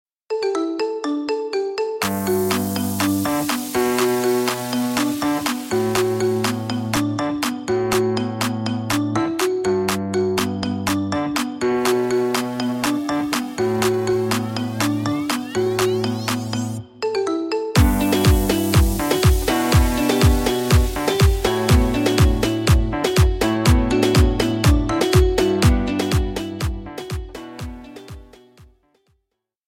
Marimba dubstep remix